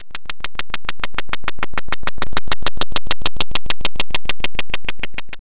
walking.wav